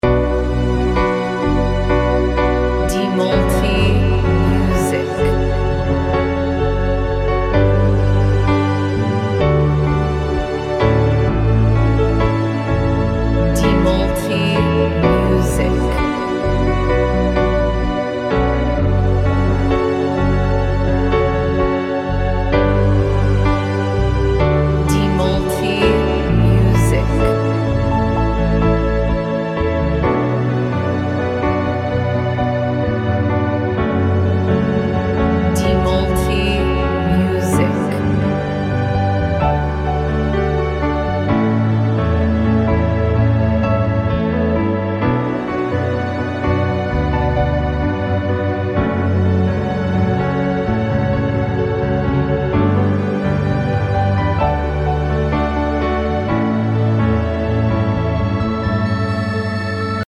Modern Piano Instrumental